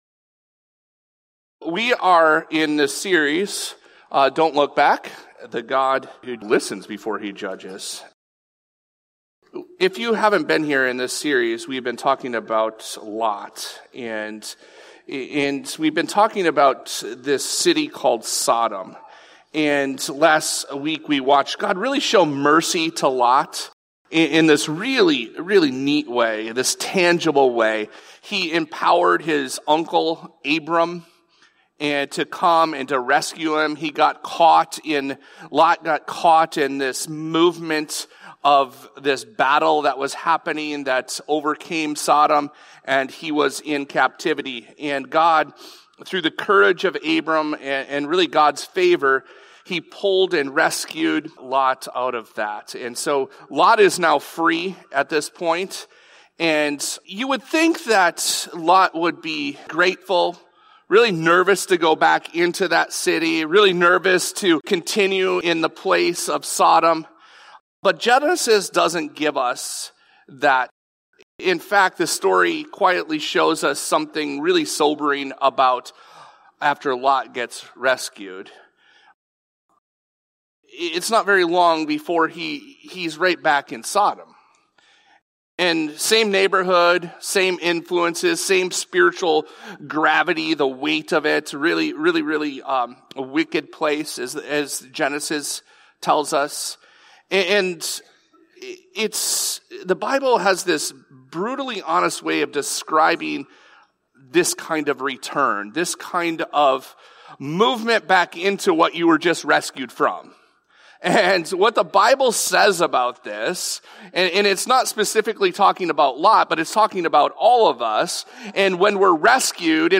This episode of the Evangel Houghton podcast is a Sunday message from Evangel Community Church, Houghton, Michigan, February 1, 2026.